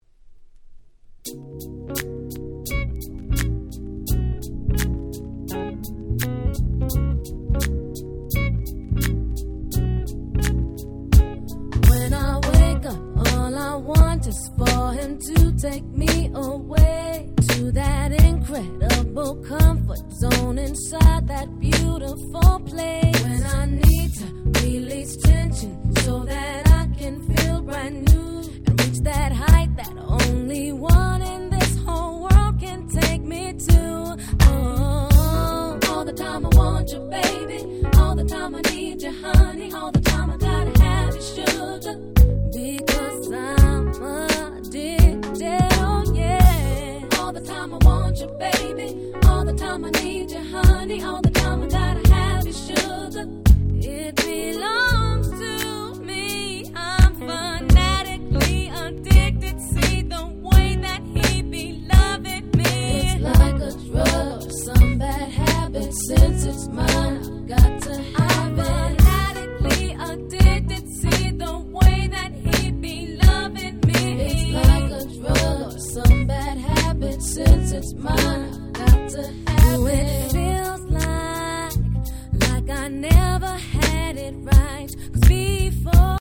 02' Very Nice Neo Soul / R&B !!